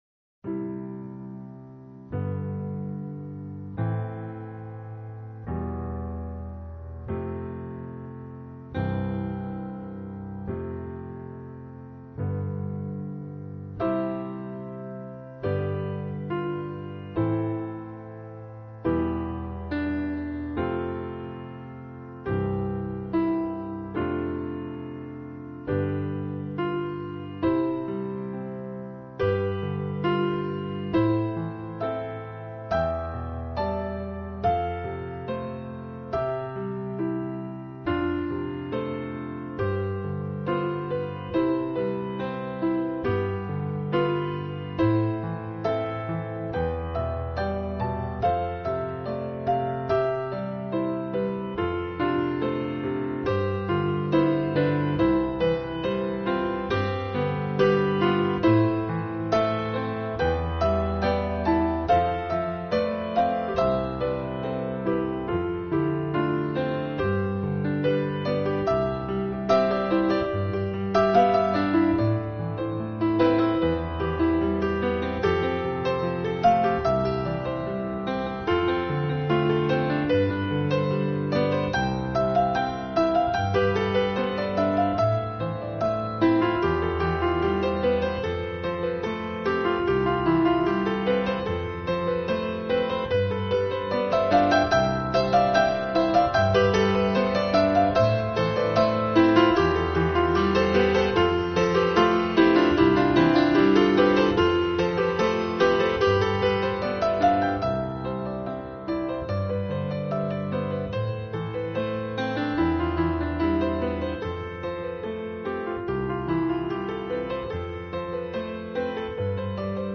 0212-钢琴名曲卡农.mp3